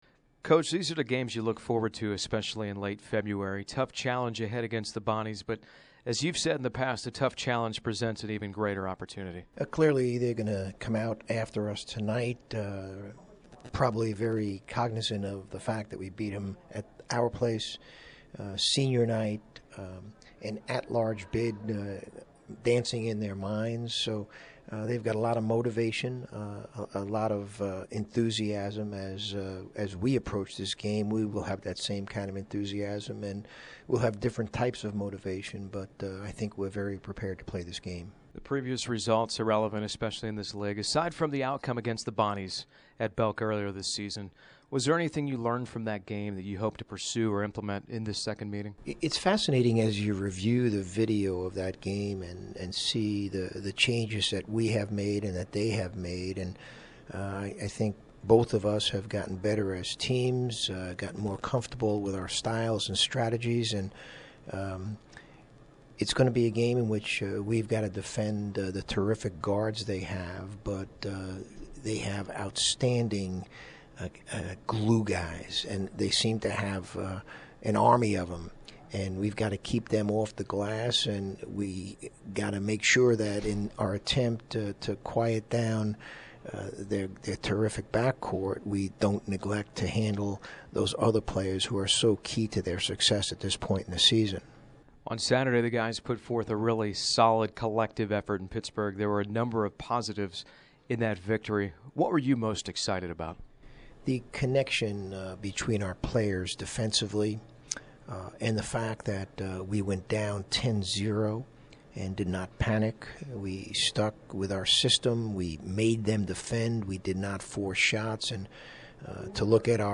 Pregame Interview